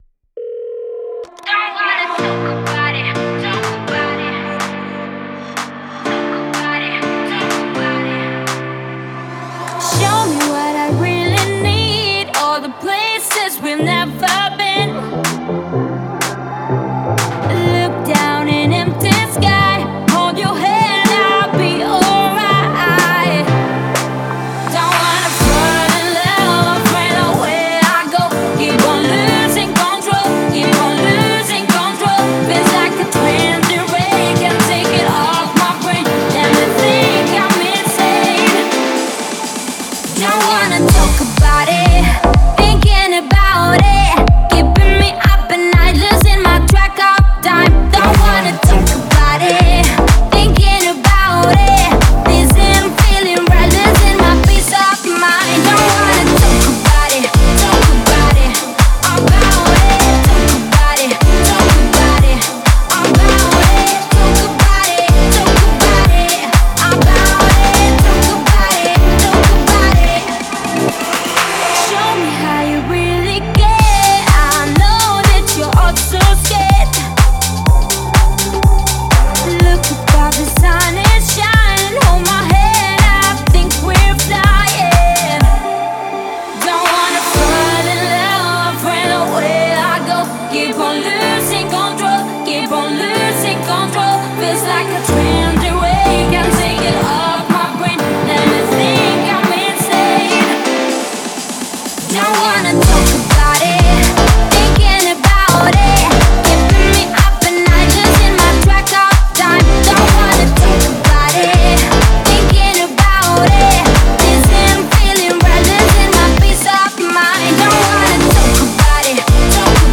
это яркая и энергичная композиция в жанре поп-музыки